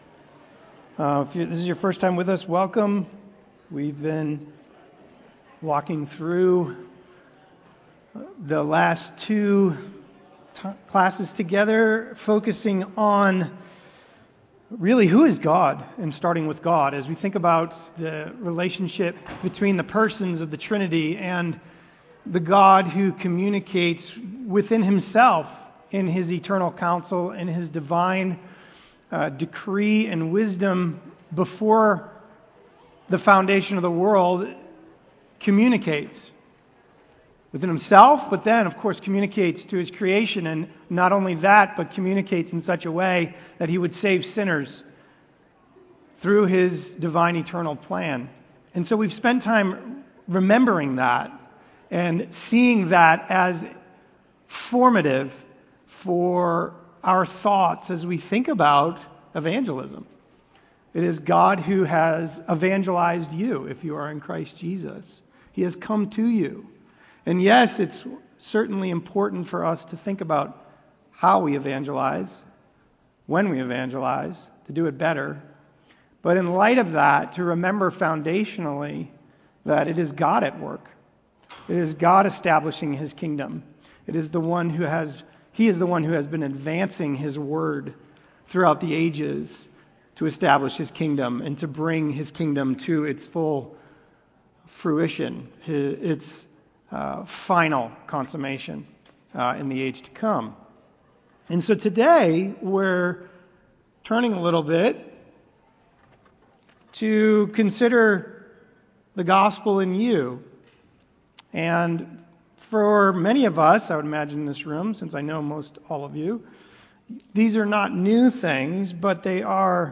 Lesson-3-The-Gospel-and-You-Part-1-2.mp3